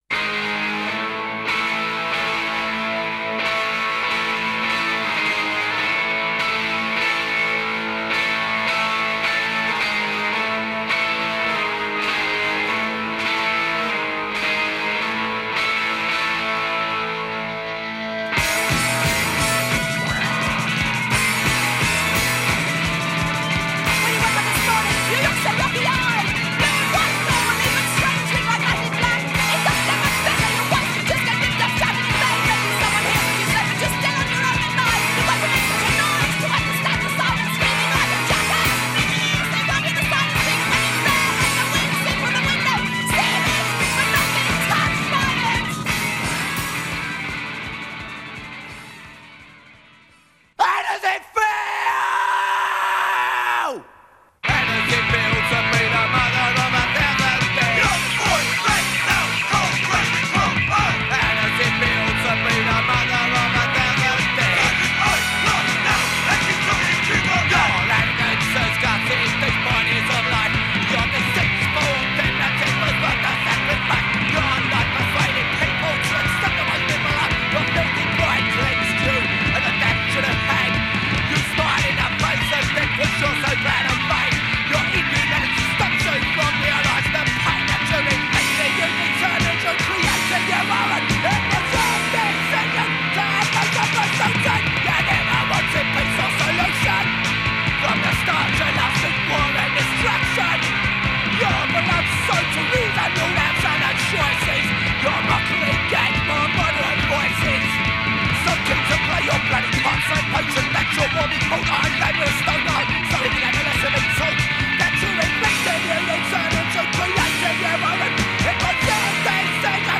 Full song in mono